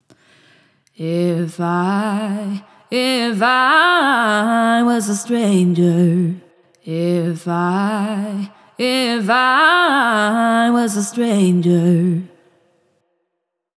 Singer before Vocal Cleanup
VocalCleanUp_on.wav